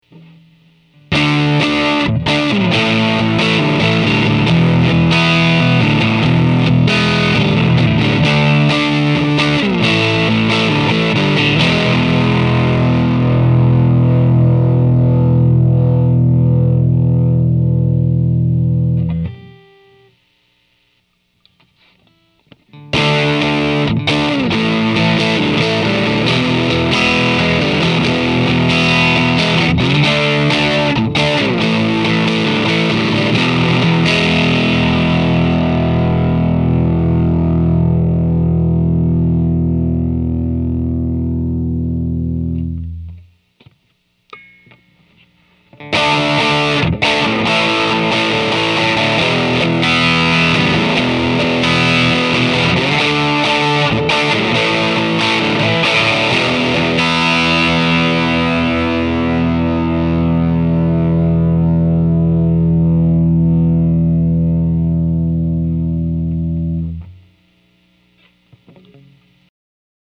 This next clip demonstrates how the guitar sounds through a fully cranked up amp (Aracom VRX22 with 6V6’s). The three parts feature the neck, middle, and bridge positions of the same chord progression, respectively:
teledirty.mp3